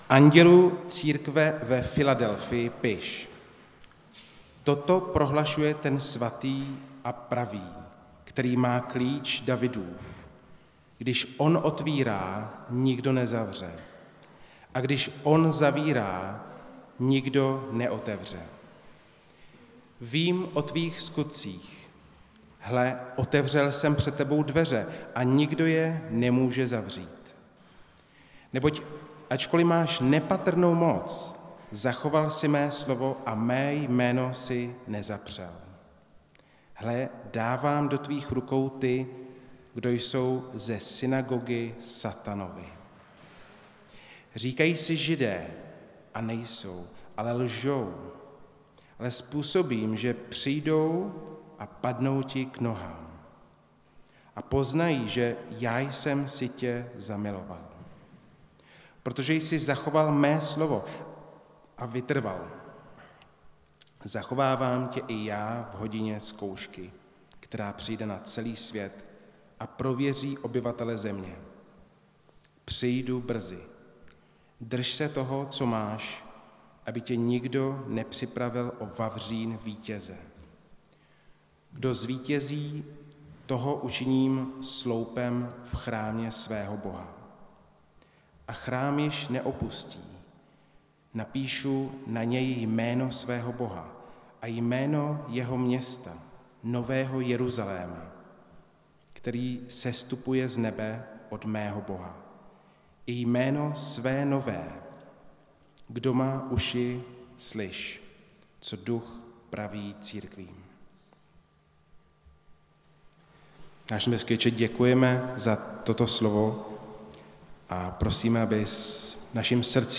Nedělení kázání – 19.6.2022 List do Filadelfie